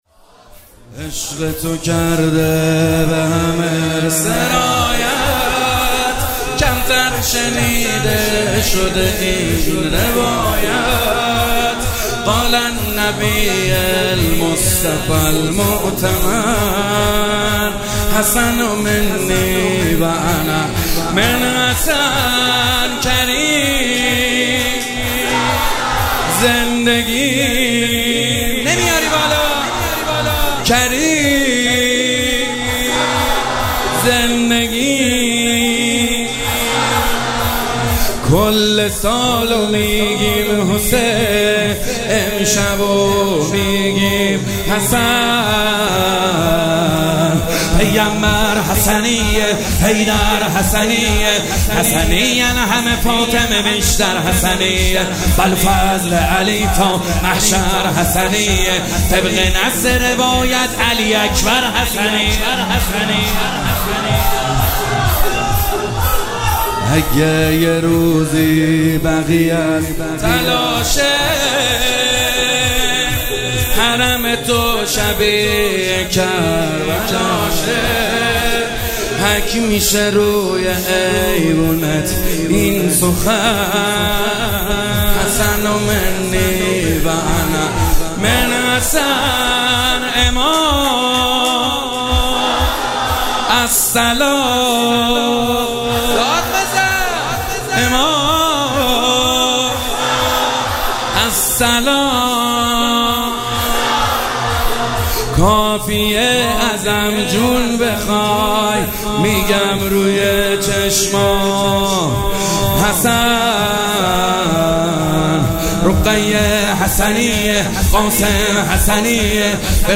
شب دوم فاطمیه دوم
شور